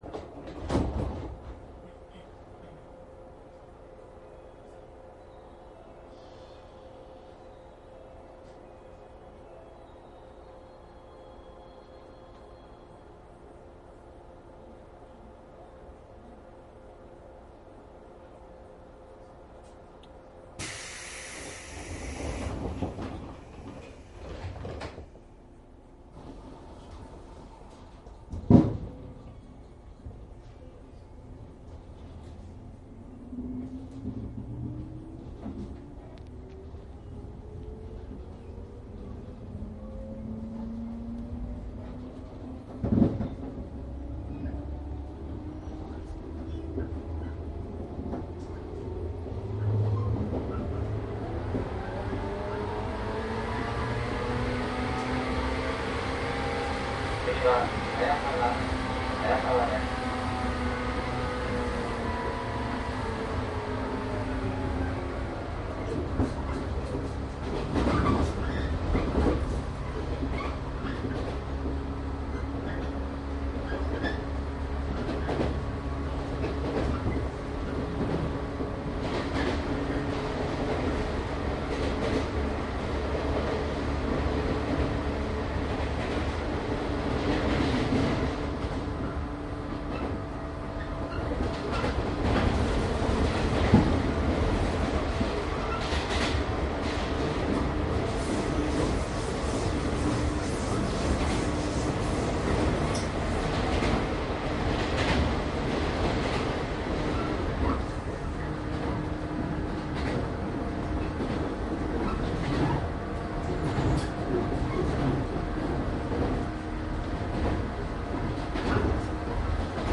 東海道線 上り 113系  走行音CD
後半の車内放送は音量が低いです。0番台の新製冷房車と2000番台とでブレーキのエアー音の違いなどが聞き取れます。前半部分はカーブが多く制限もあるのでノッチの操作で発生する断流器の音も楽しめます。
録音車両モハ113－317・1064
サンプル音声 モハ113－317.mp3
いずれもマイクECM959です。
実際に乗客が居る車内で録音しています。貸切ではありませんので乗客の会話やが全くないわけではありません。